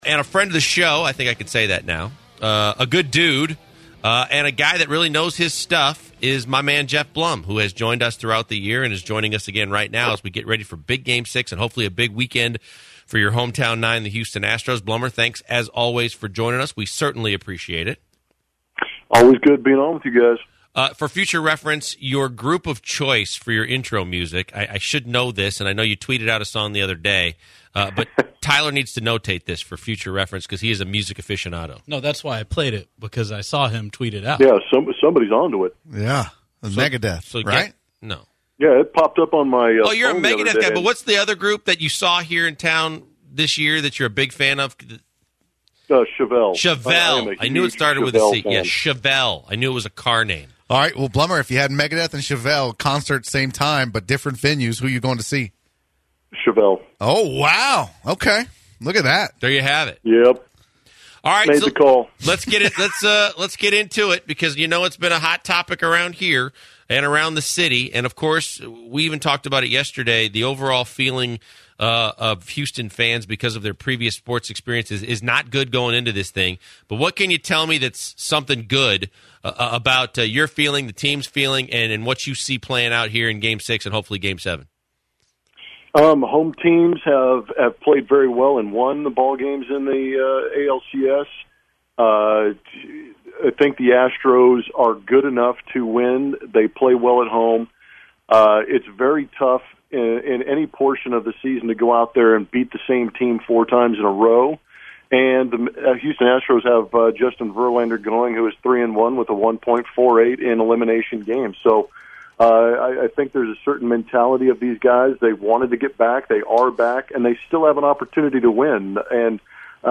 10/20/17 Geoff Blum Interview